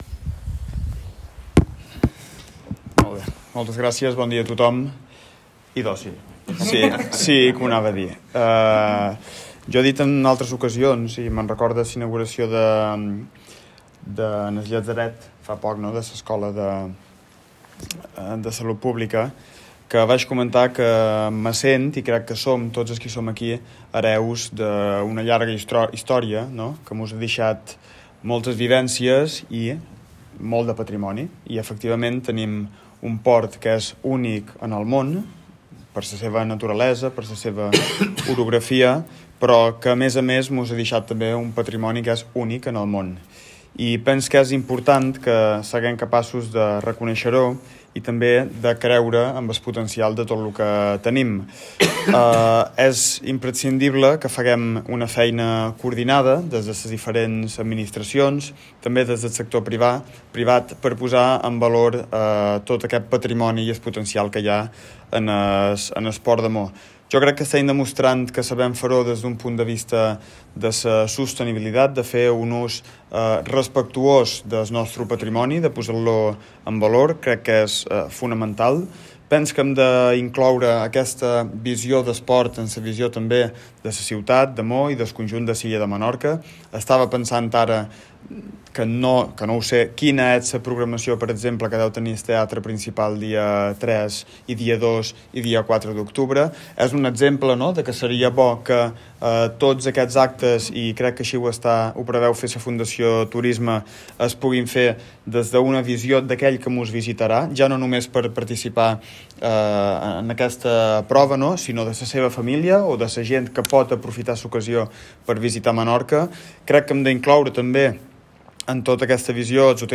Ha cerrado el acto el Alcalde de Maó, Héctor Pons, ha puesto en valor la visión del puerto de Maó y nuestro Patrimonio, y cultura haciendo referencia poner en comunión los diferentes calendarios tanto deportivos como culturales, para que en un futuro Menorca y Maó en particular, siga siendo un destino turístico de primer nivel mundial.